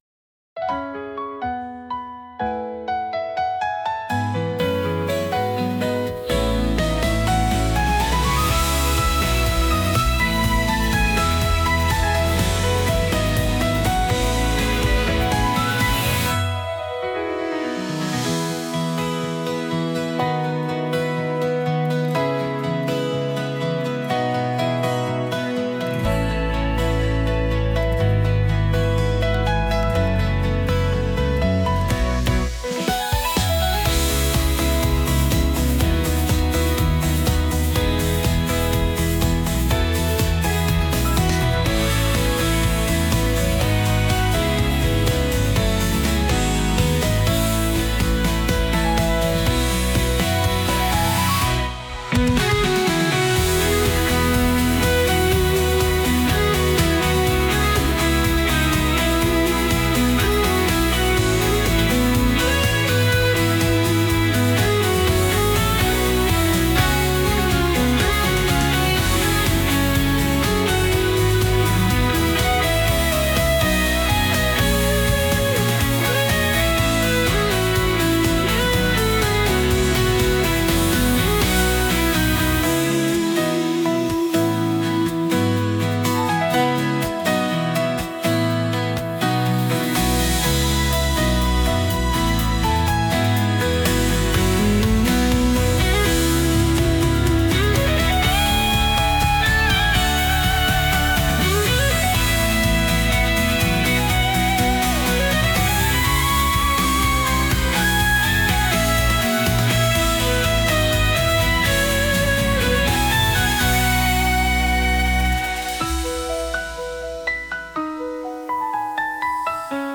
夏の青空に入道雲が浮かんでいるような勢いのピアノ曲です。